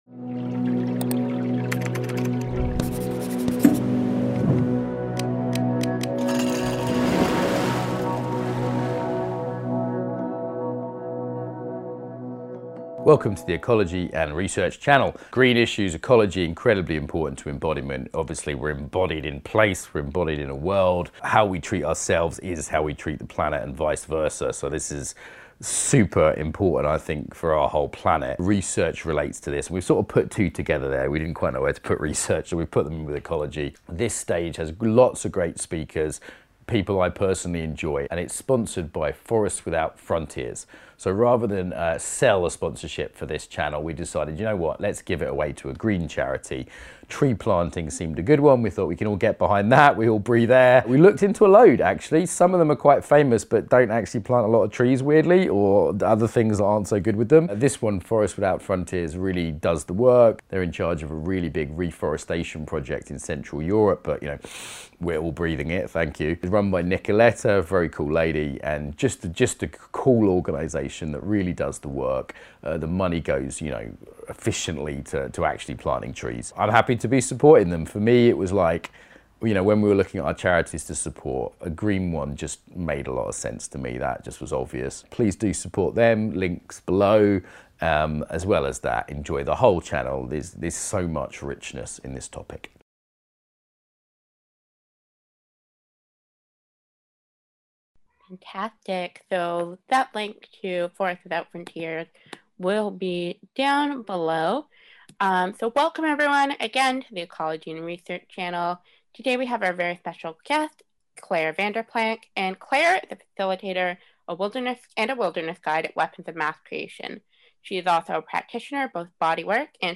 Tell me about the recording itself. This session will include some practice and is best experienced with some nature in visual or auditory sensing distance.